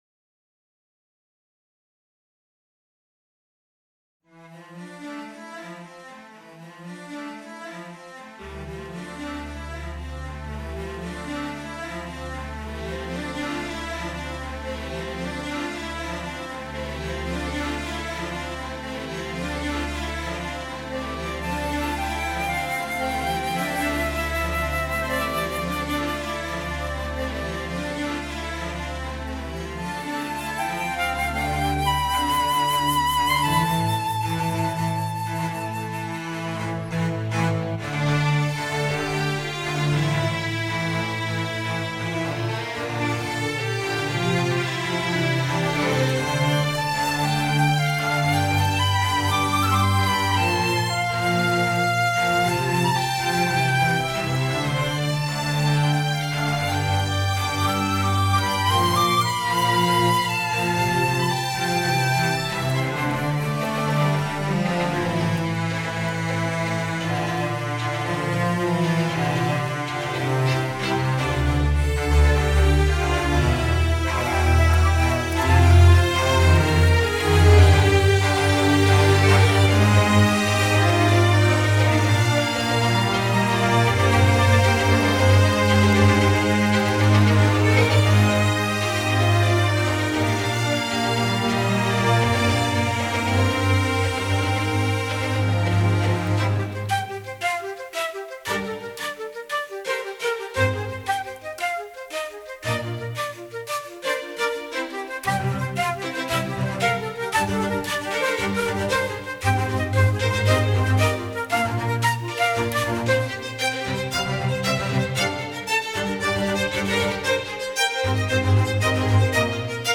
para flauta y quinteto de cuerdas